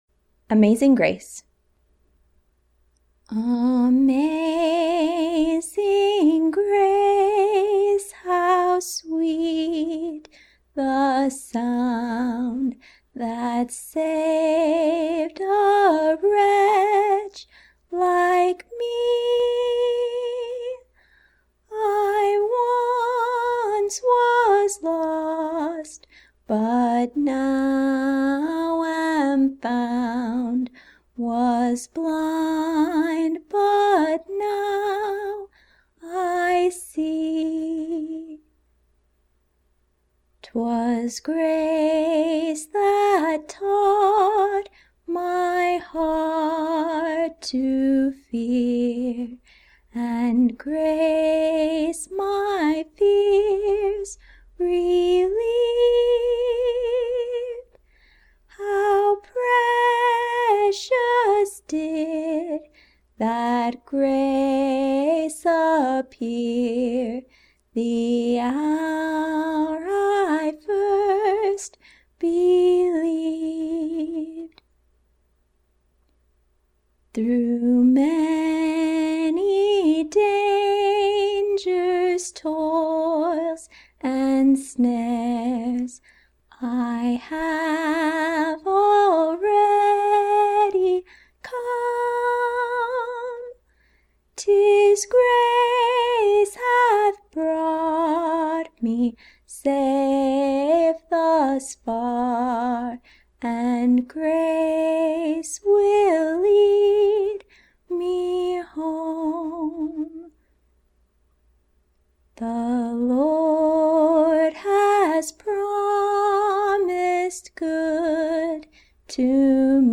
Amazing Grace Spiritual Amazing Grace how sweet the sound that saved a wretch like me.